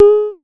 Vermona Perc 04.wav